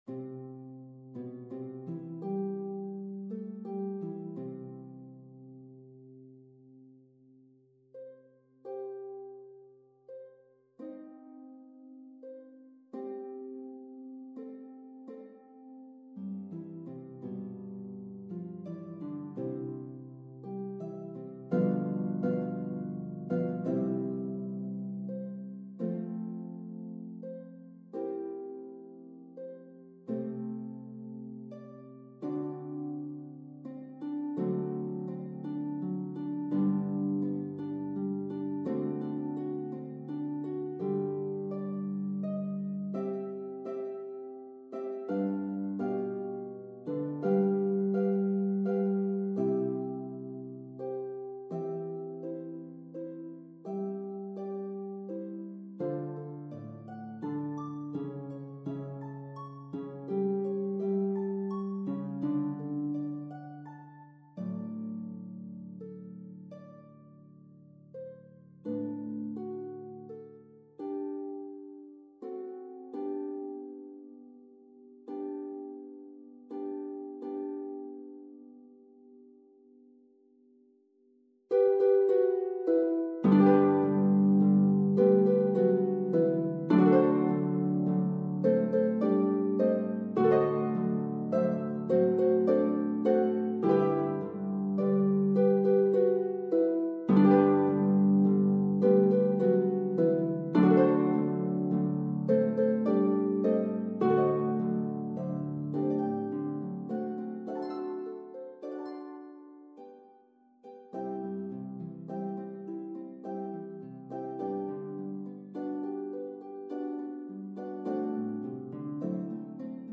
Pedal Harp Score & Parts, 54 pages.